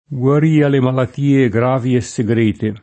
malattia [ malatt & a ] s. f.